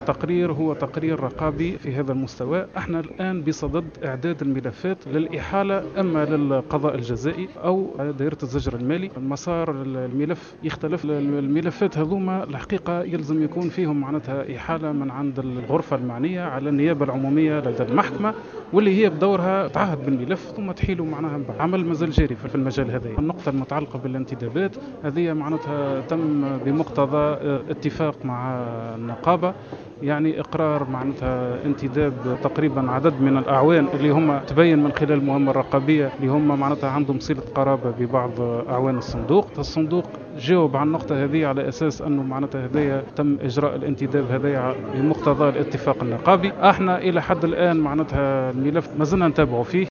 Un conseiller auprès de la Cour des Comptes a annoncé, ce lundi, lors d’une séance d’audience au parlement, que la Cour a relevé de graves dépassements au niveau de la gestion de la CNRPS qui ont même touché les versements des pensions des retraités. Il a ajouté que ces dépassements sont répréhensibles et leurs auteurs sont passibles de peines pénales.